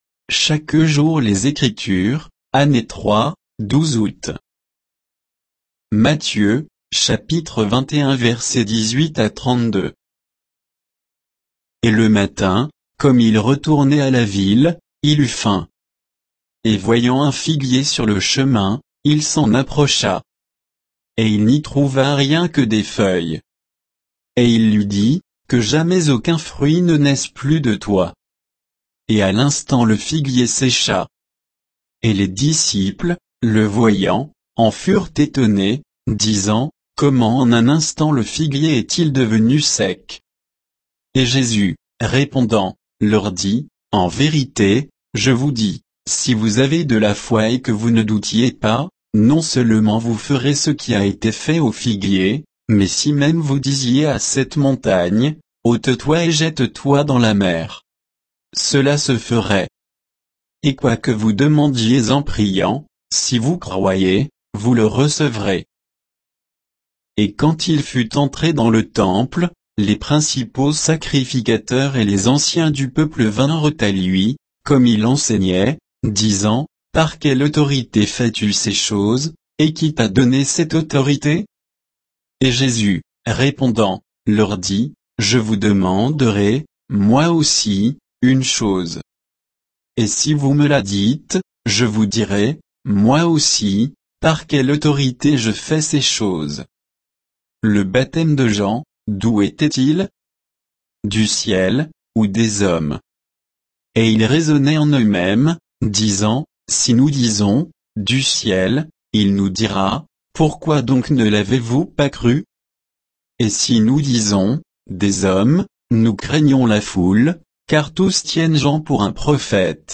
Méditation quoditienne de Chaque jour les Écritures sur Matthieu 21